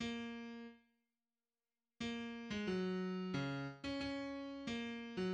{\clef bass \tempo 4=90\key b \major \set Score.currentBarNumber = #1 \bar "" ais4 r2 ais8. gis16 fis4 cis8 r16 cis' cis'4 ais8.~ fis16 }\addlyrics {\set fontSize = #-2 - Migh- ty as li- ons they fight for each o- ther For free- dom and jus- tice and e- qua- li- ty. We'll car- ry the ban- ner as sis- ters and bro- thers Wa- king the world to the light of the day. As friends and com- panions, as com- rades and lo- vers A- bet- er froy- en show us the show us the way } \midi{}